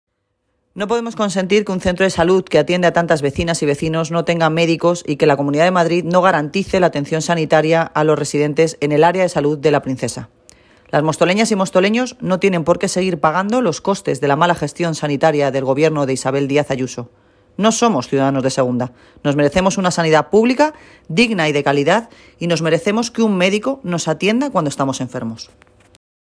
Declaraciones Noelia Posse situación Centro de Salud La Princesa